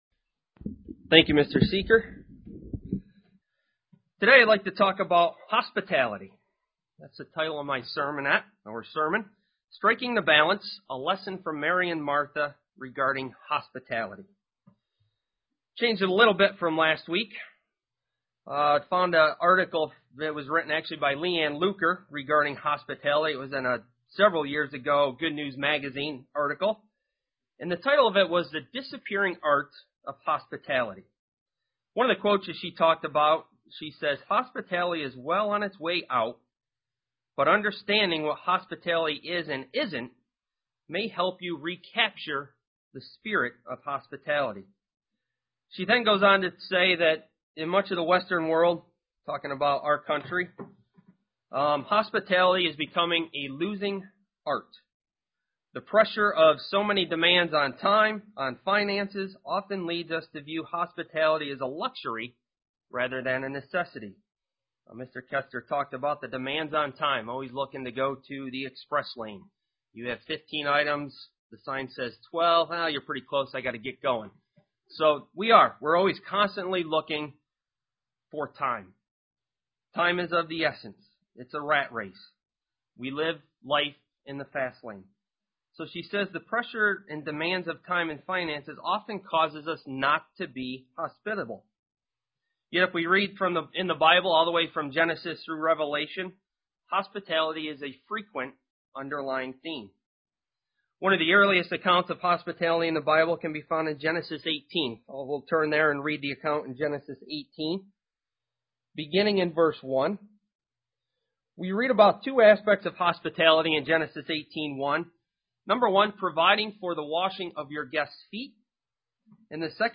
Print What is hospitality and how we should display it UCG Sermon Studying the bible?
Given in Elmira, NY